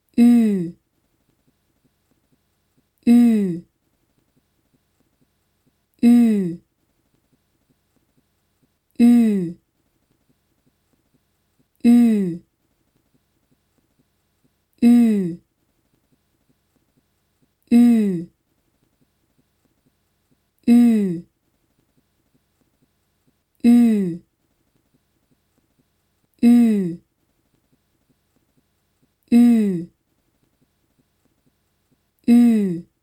Laut-Ue-lang_einzeln.mp3